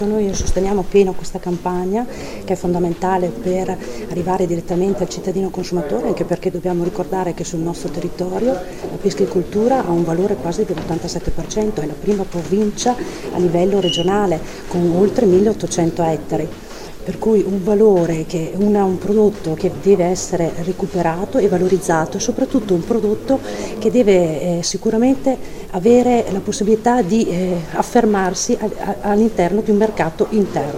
Al microfono della nostra corrispondente